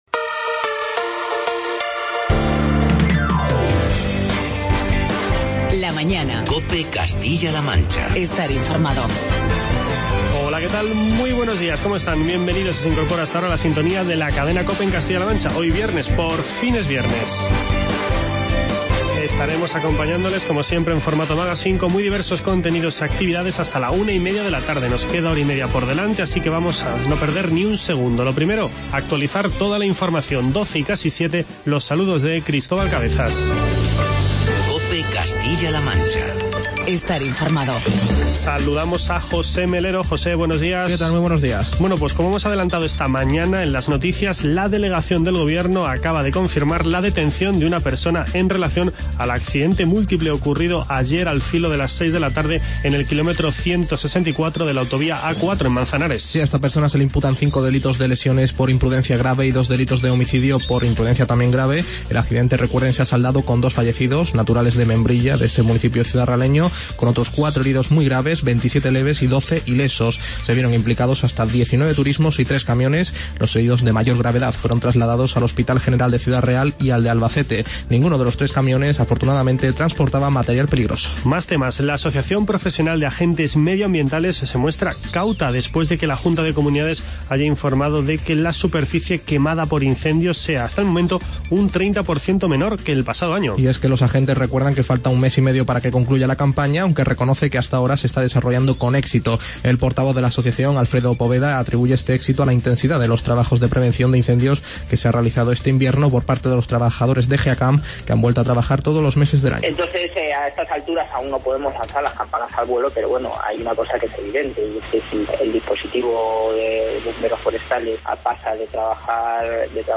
Entrevista con Francisco Nuñez, portavoz adjunto del Grupo Parlamentario Popular.